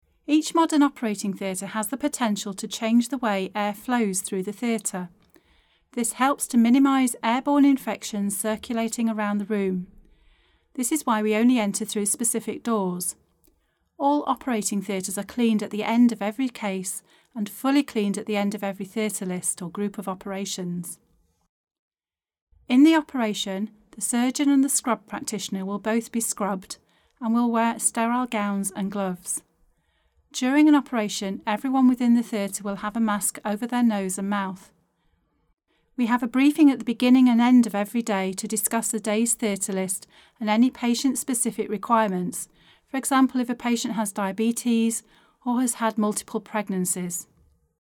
Read Aloud